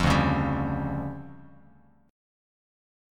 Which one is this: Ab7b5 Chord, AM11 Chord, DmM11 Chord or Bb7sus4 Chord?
DmM11 Chord